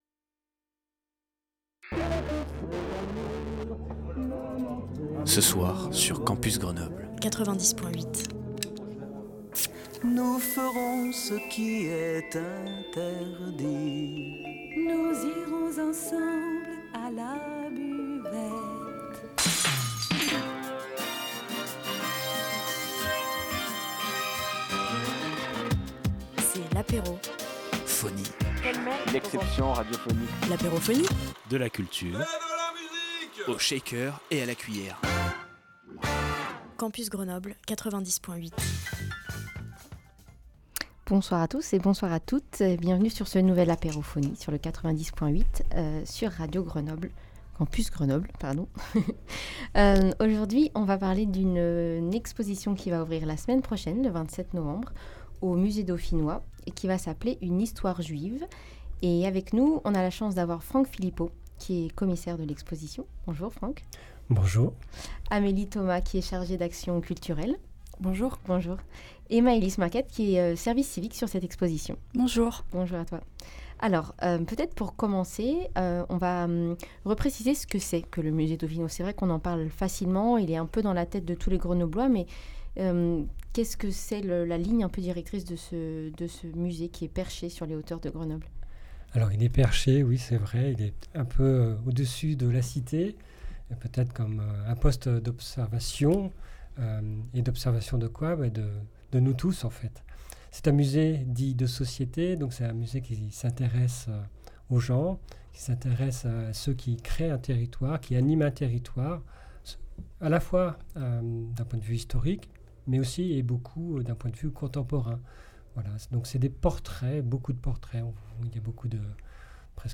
nous avons reçu sur le plateau de Radio Campus